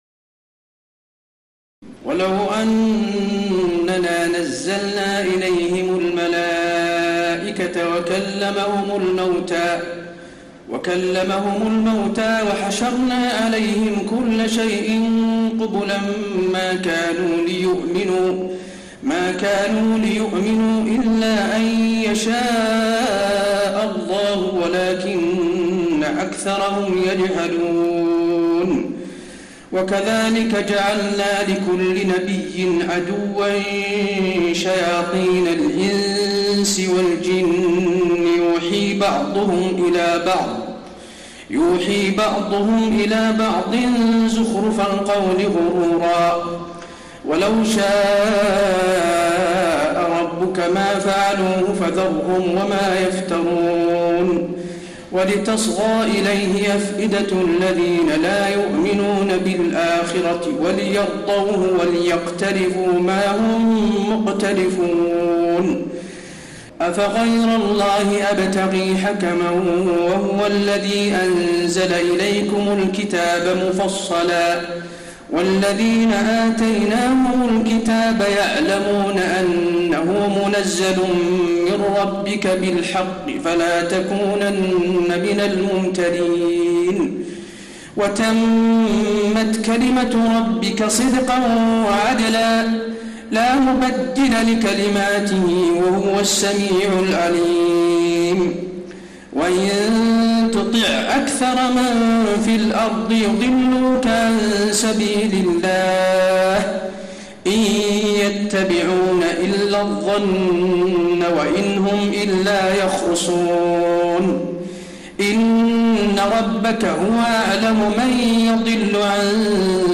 تراويح الليلة الثامنة رمضان 1432هـ من سورة الأنعام (111-165) Taraweeh 8 st night Ramadan 1432H from Surah Al-An’aam > تراويح الحرم النبوي عام 1432 🕌 > التراويح - تلاوات الحرمين